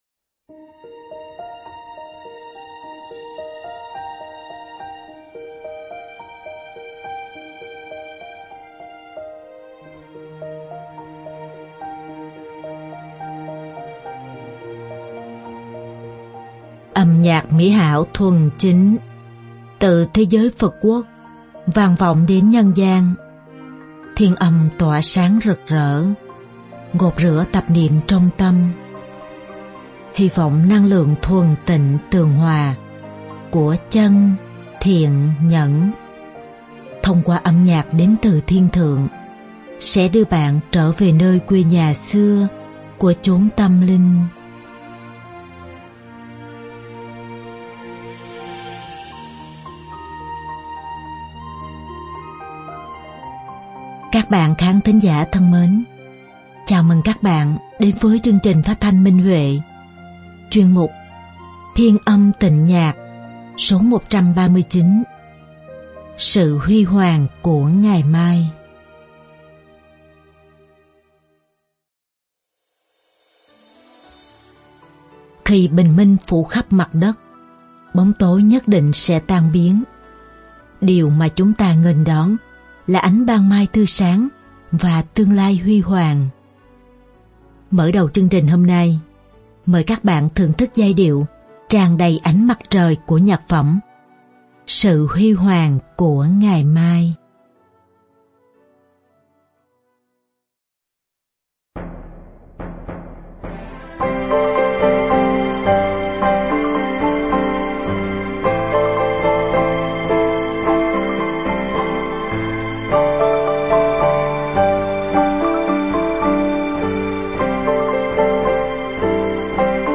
Nhạc phẩm
Đơn ca nam